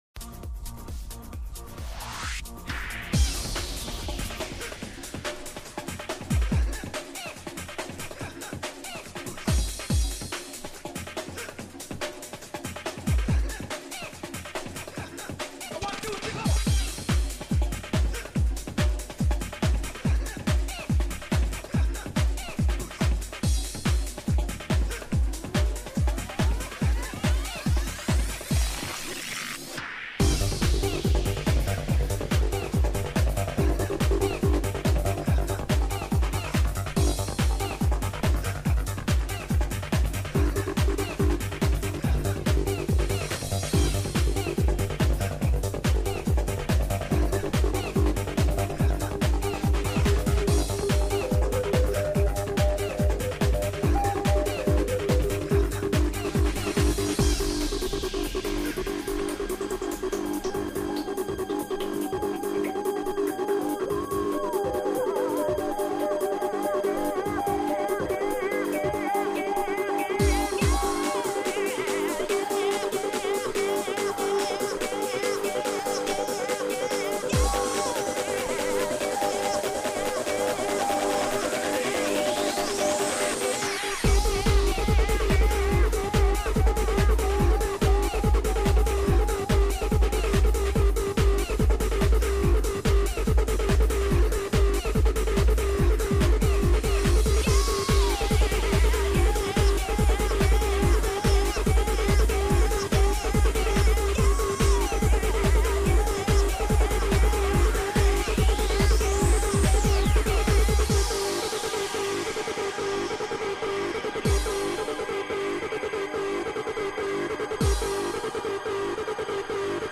Breaks, Oldskool and Dnb
Oldskool Drum & Bass Breaks